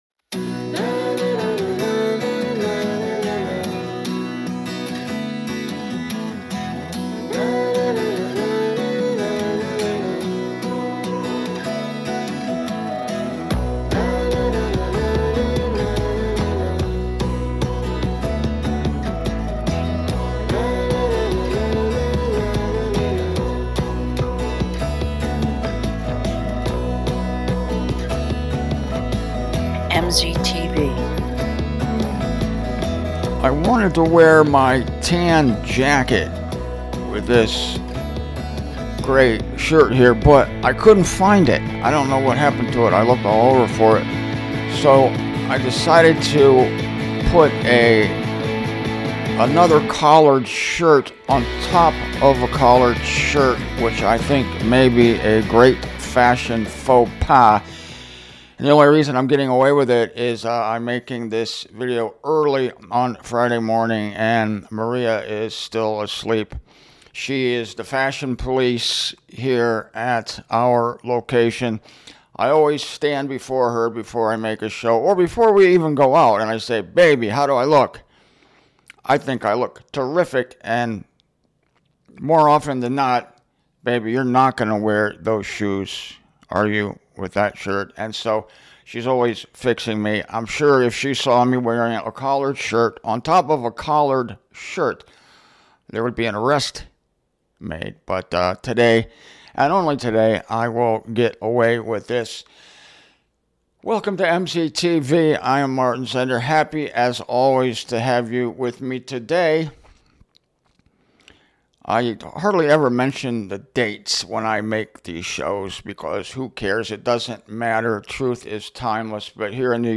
It's a good thing for Christians that there is no such thing as an eternity in hell for those who reject Christ's free gift. This messages was delivered in Des Moines, Iowa, on September 17, 2023.